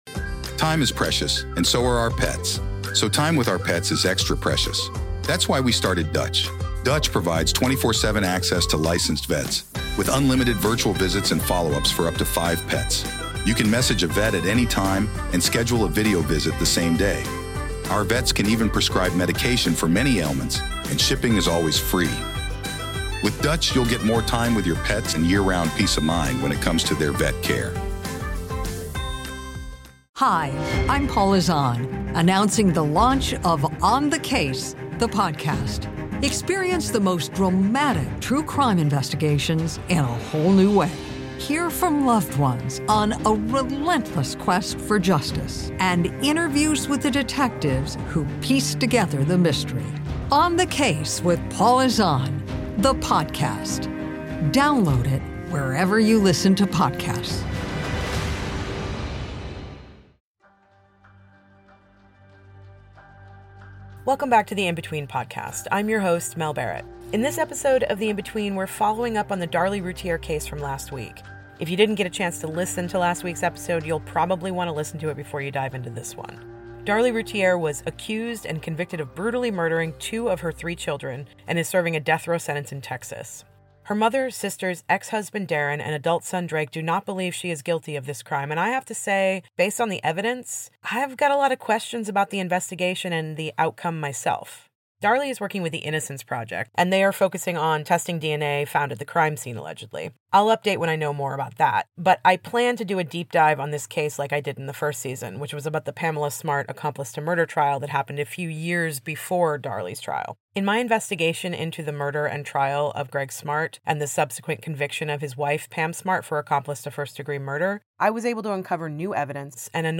In a candid and emotional interview